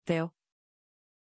Pronuncia di Teo
pronunciation-teo-tr.mp3